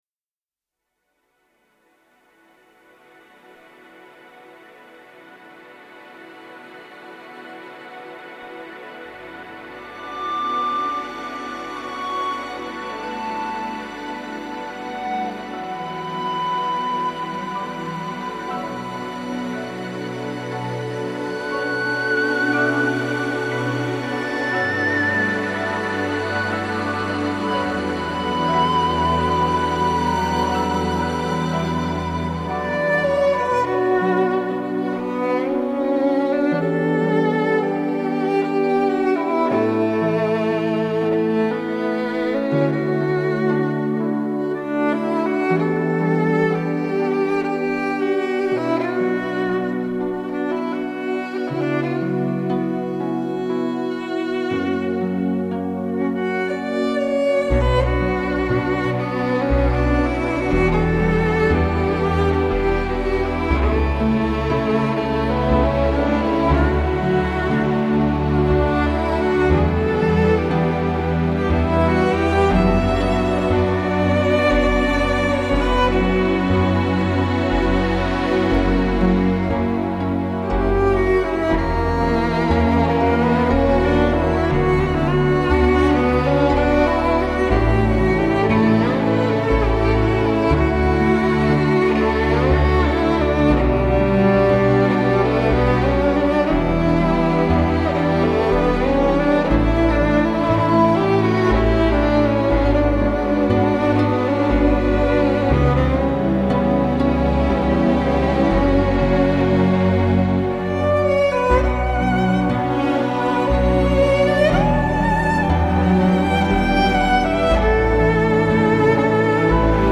这段优美旋律与和声交织的音乐，能让人联想到自然风景，带来放松的体验。
当然，哨笛和爱尔兰风笛等传统乐器依然保持着其田园诗般的音色，唤起令人安心的怀旧之情。